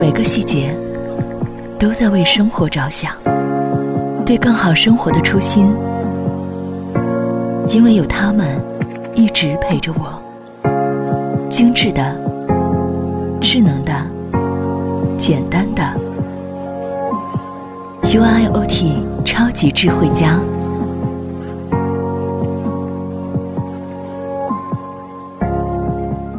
女267-广告—UIOT超级智慧家
女267专题广告解说彩铃 v267
女267-广告-UIOT超级智慧家.mp3